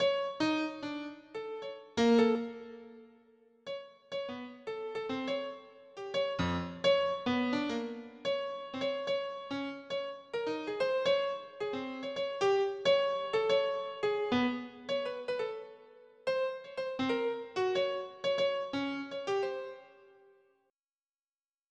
• Качество: 320, Stereo
без слов
клавишные
эксперимент